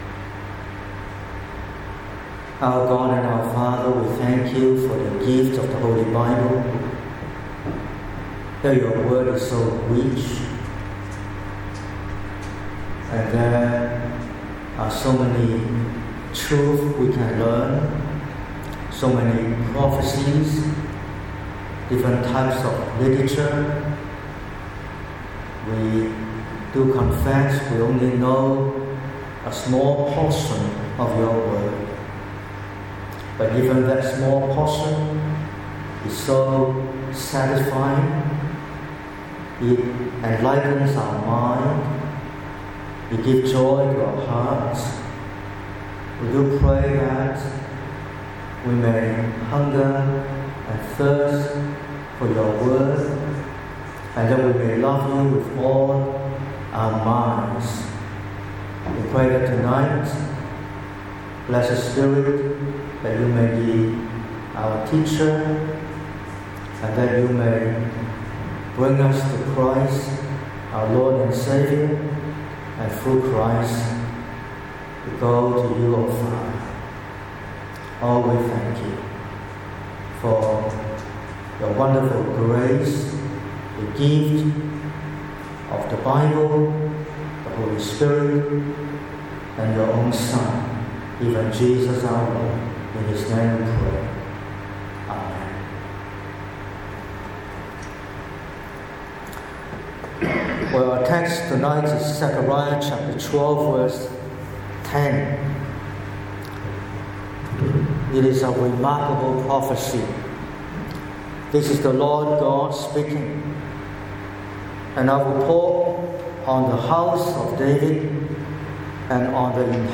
22/03/2026 – Evening Service: Spirit of prayer